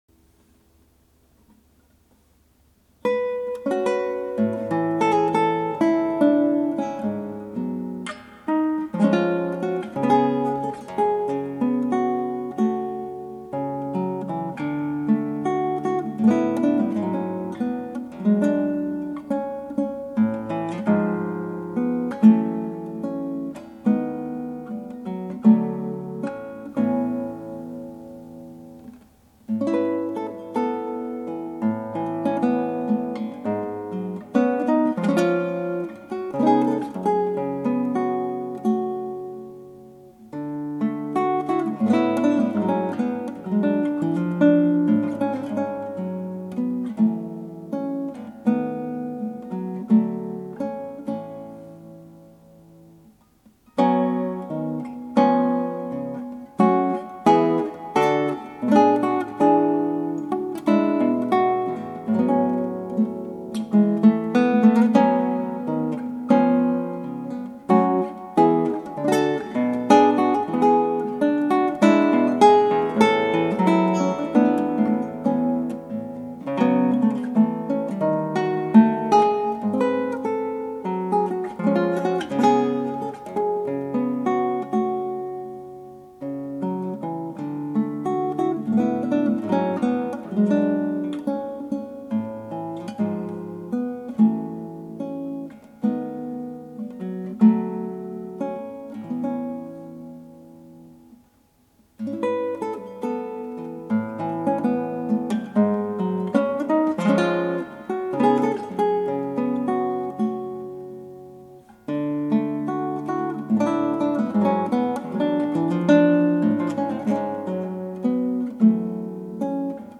ギターの自演をストリーミングで提供